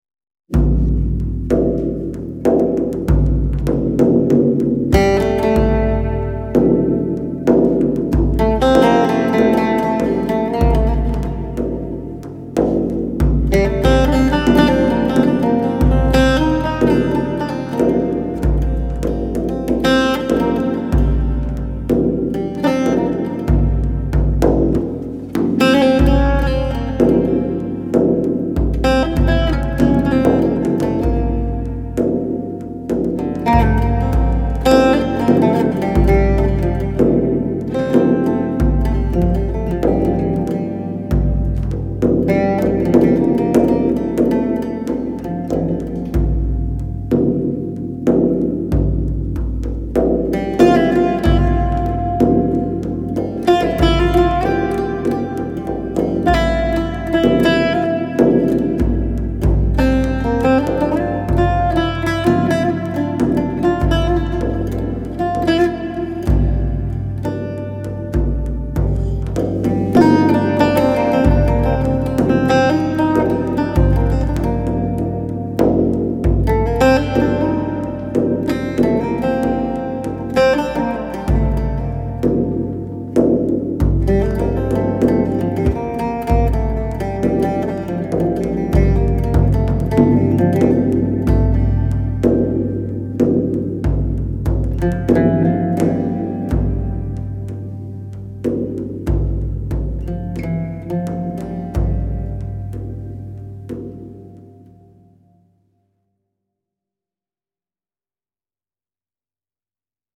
Possum Hall Studios